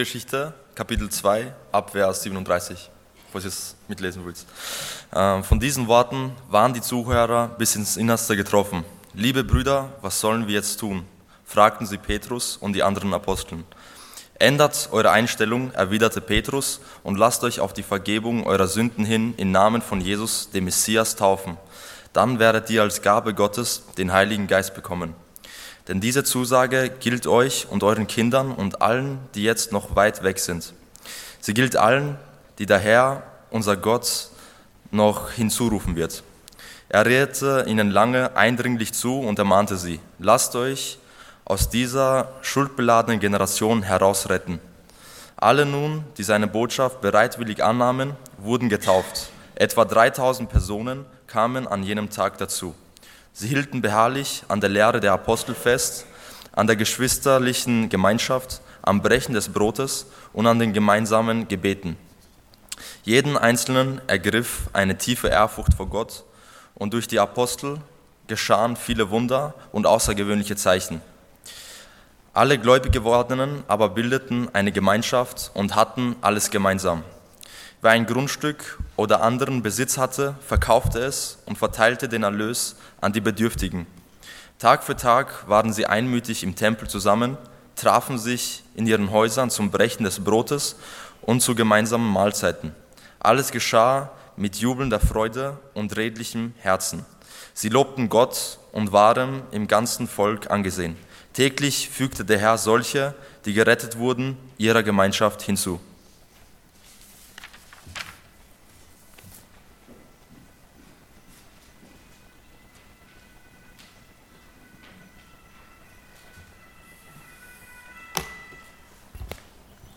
Sonntag Morgen